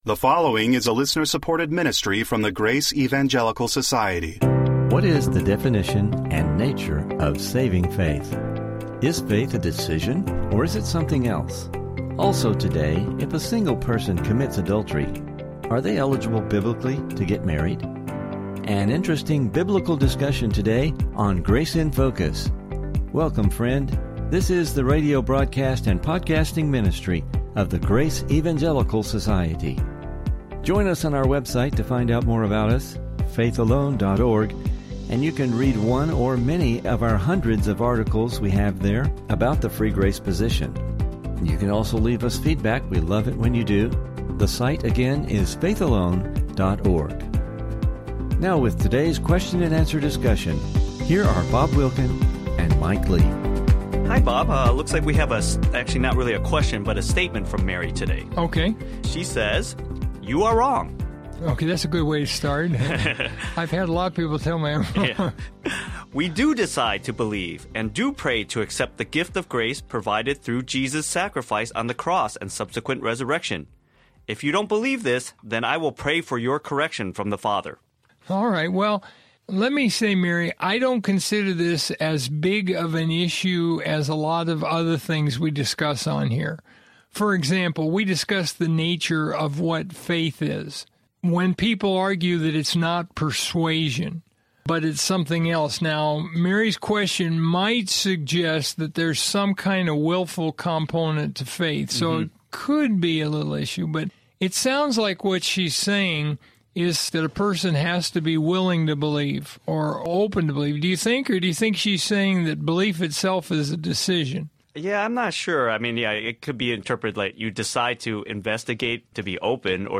Please listen for some interesting Biblical discussion regarding these subjects!